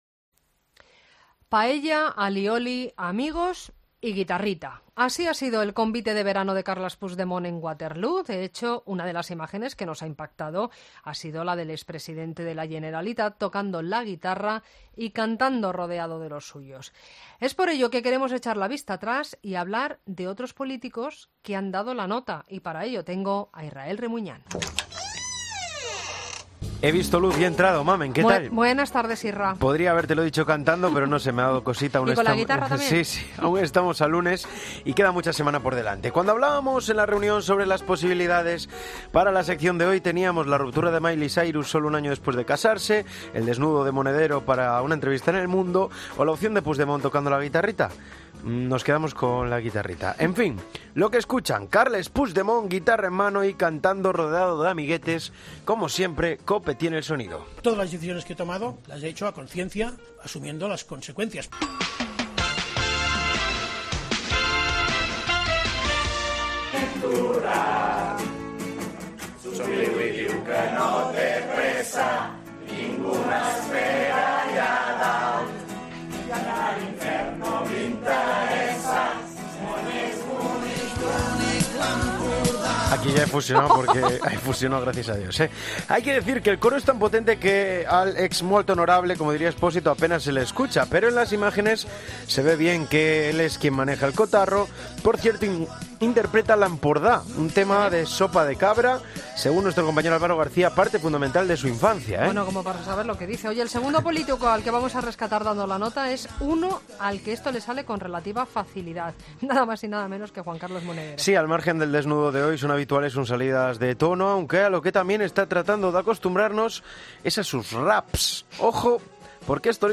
Empezamos con Carles Puigdemont guitarra en mano y cantando rodeado de amiguetes. Hay que decir que el coro es tan potente que al “ex molt honorable” a penas se le escucha pero en las imágenes se ve bien que él es quien maneja lo que sucede.
El líder de Podemos se lanzó a cantar con guitarra en una entrevista en el Hormiguero.